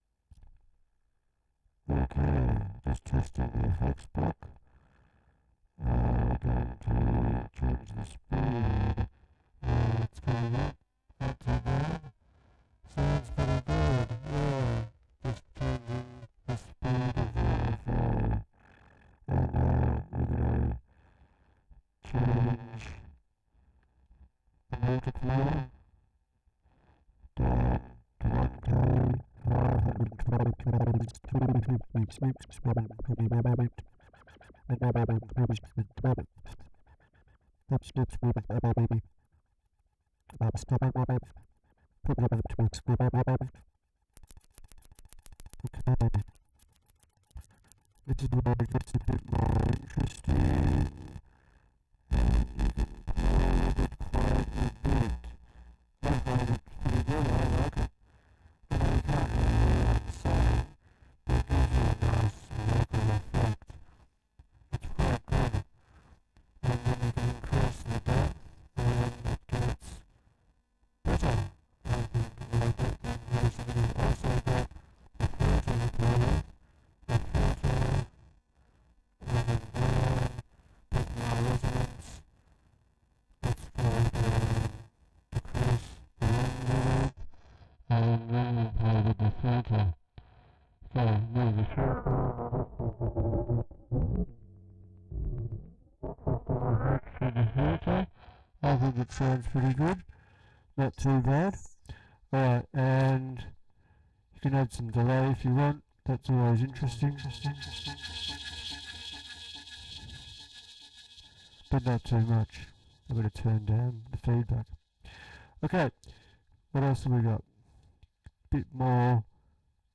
Some vocoder type sounds, lets say vocal effects processing! Rough AF but should give you an idea of whats possible, you can really fine tune it, also use different fiter types to get some great sounds. I actually got caught up playing with the gain and getting the fx block to self oscillate, some amazing old school analog synth drones atmos and effects that you can parameter lock and get nice rythums with trigs.